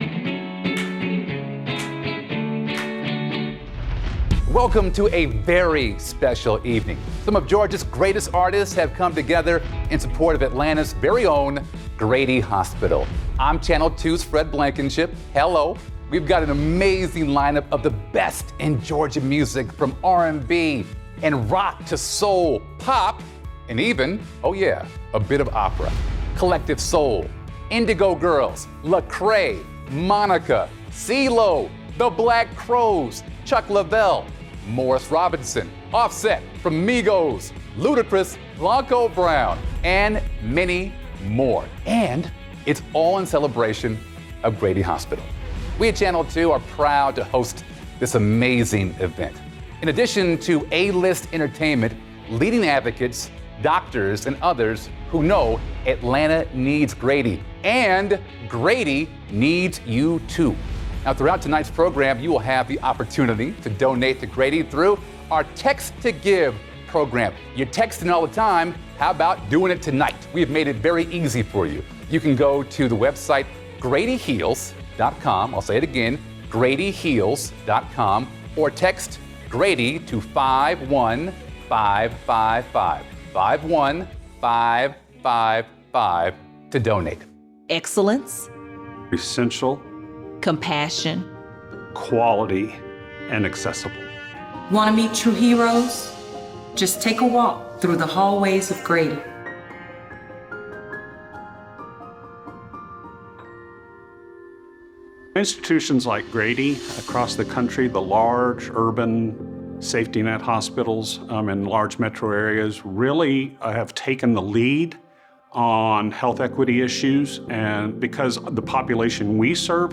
(captured from youtube)